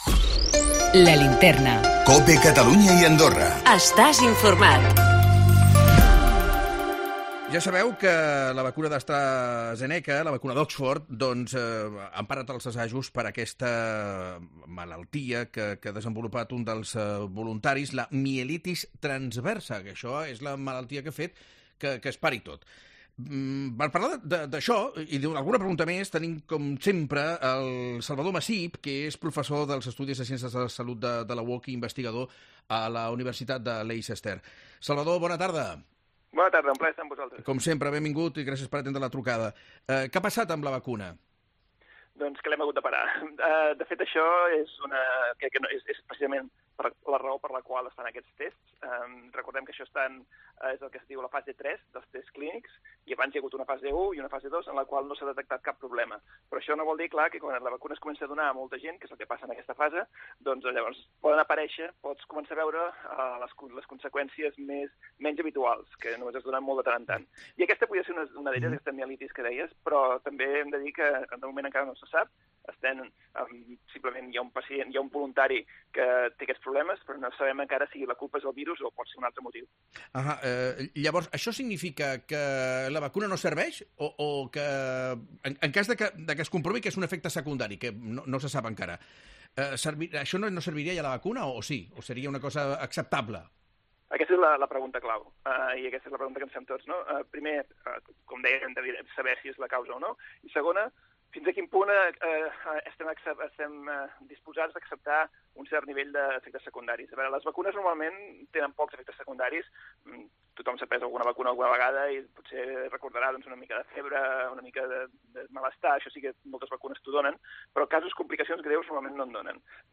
"El que encara no se sap és si aquesta malaltia és culpa del virus o per un altre motiu. Es trigarà unes setmanes a saber-ho" ens diu l'investigador